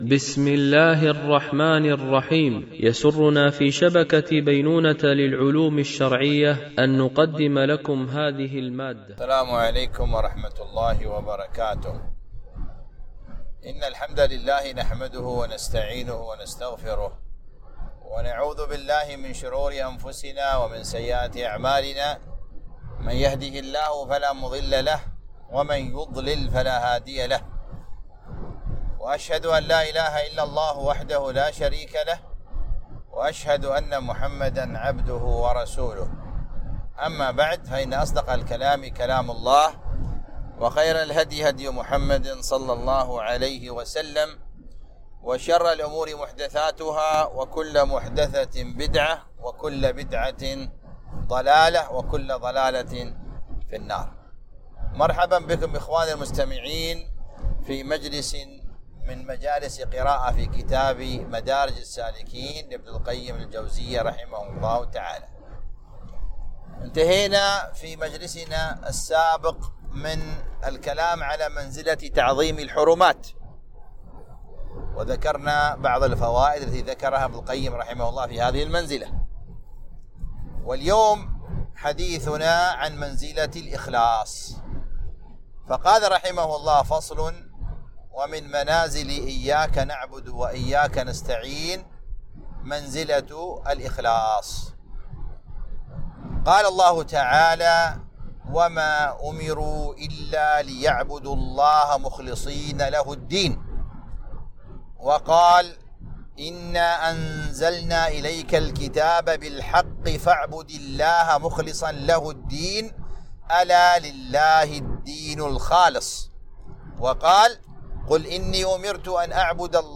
قراءة من كتاب مدارج السالكين - الدرس 61